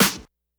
Snares
Snare_32.wav